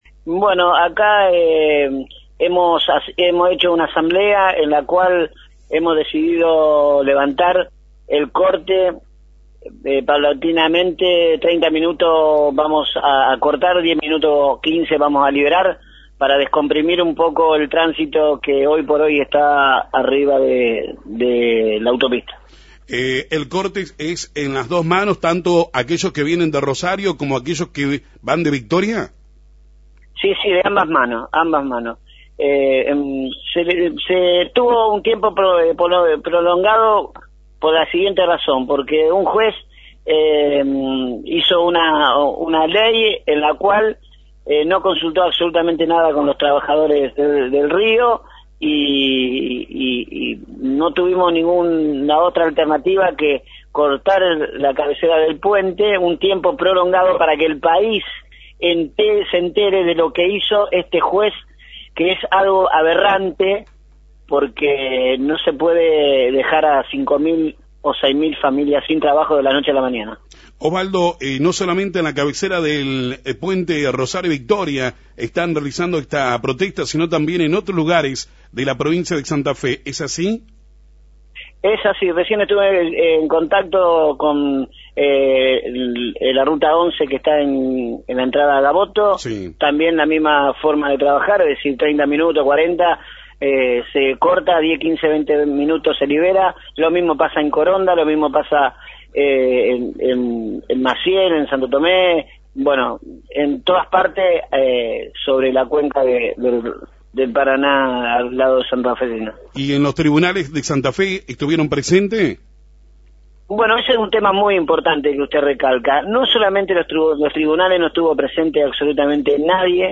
“Nos sentimos desprotegidos” pescadores en la segunda jornada de corte hablaron en FM 90.3
pescadores.mp3